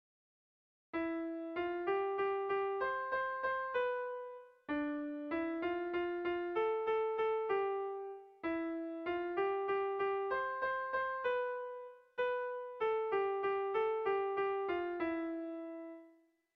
Haurrentzakoa
Lau puntuko berdina, 9 silabaz
A1A2A1B